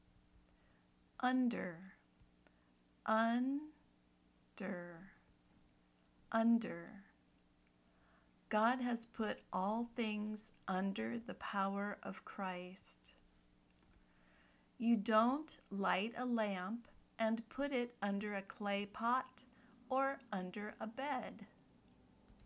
/ˈʌn dər/ (preposition)